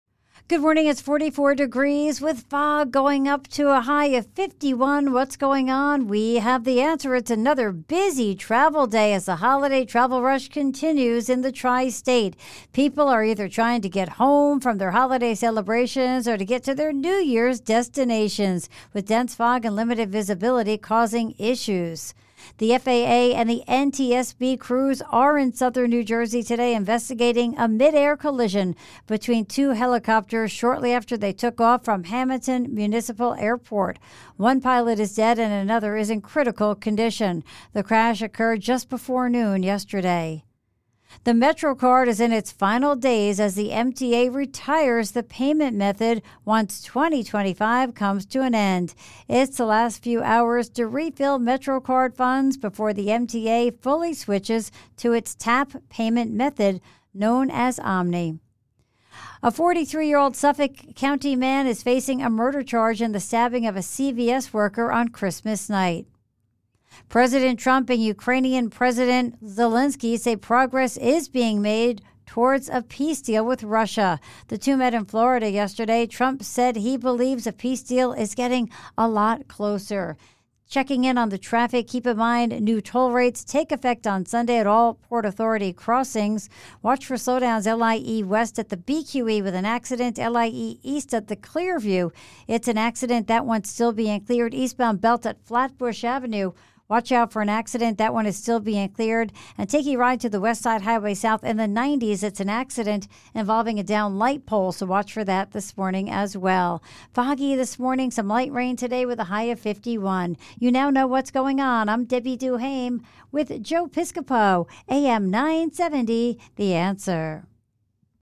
Here are some sound bites from AM970 The Answer
AM 970 The Joe Piscopo show News, Traffic Report